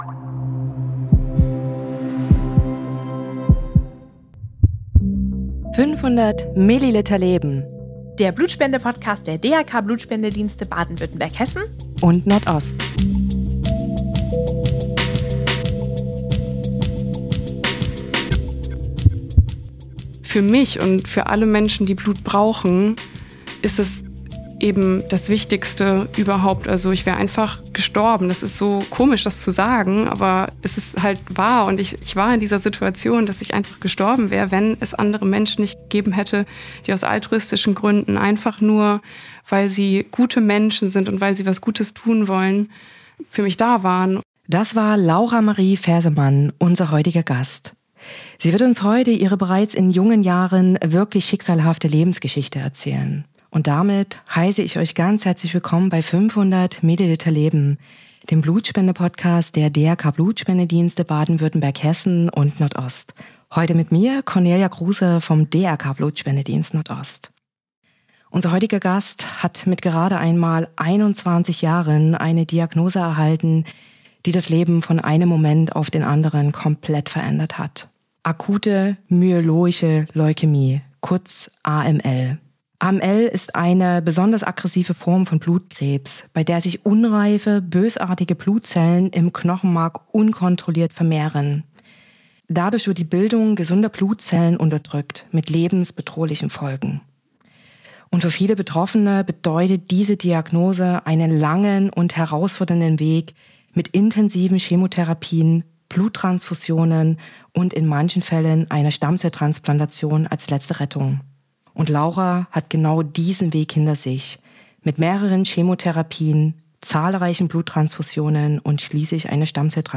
Wir lassen Patienten wie Spender zu Wort kommen, sprechen mit Experten über relevante Themen und informieren euch detailliert über die Blutspende.